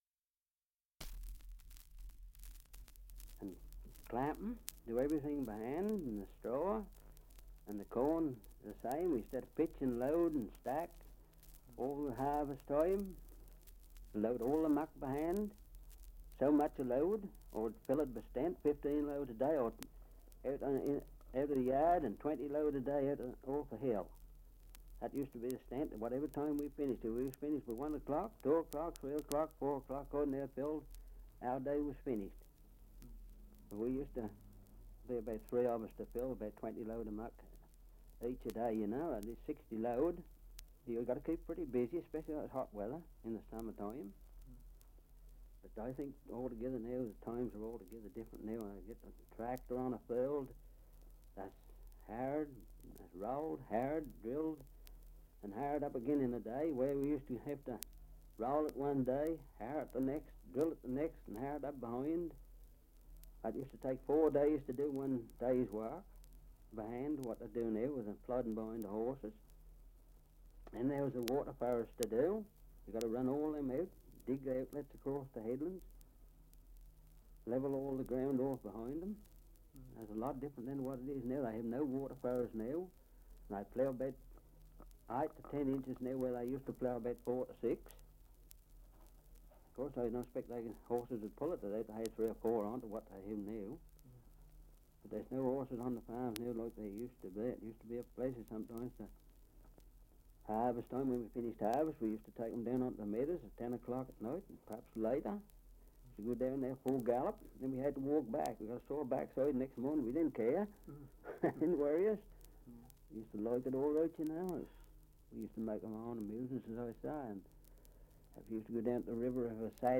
Survey of English Dialects recording in Little Bentley, Essex
78 r.p.m., cellulose nitrate on aluminium